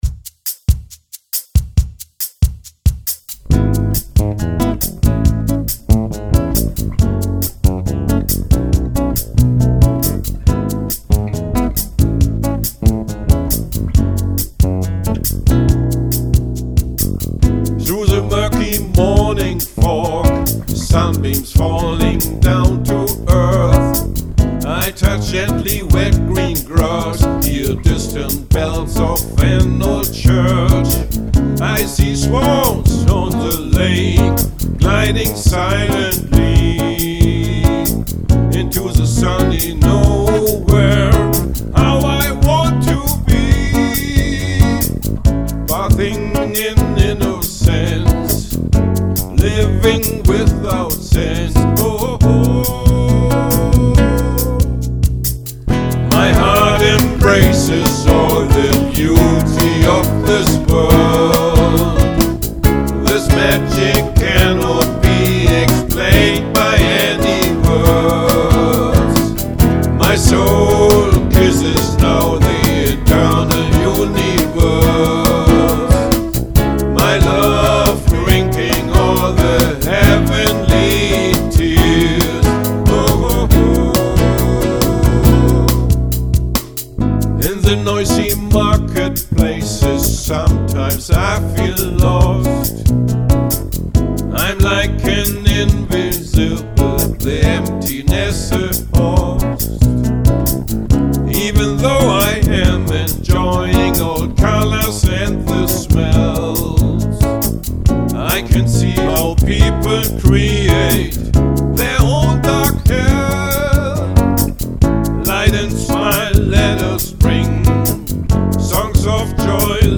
Die Audio-Tracks sind mit allen Instrumenten (und Vocals) von mir allein eingespielt worden (home-recording).
Seit Frühjahr 2012 benutze eine etwas bessere Aufnahmetechnik (mit einem externen Audio-Interface und einem besseren Mikrofon).